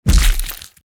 face_hit_finisher_61.wav